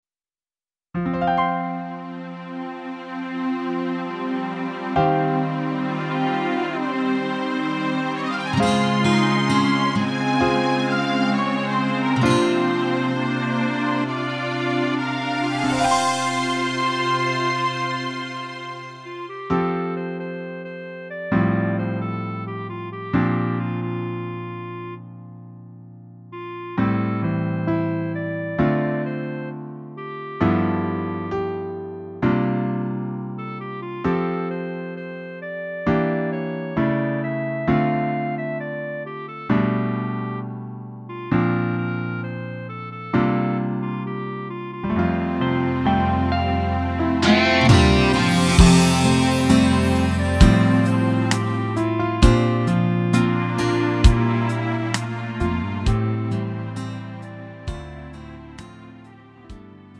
음정 원키 4:13
장르 구분 Lite MR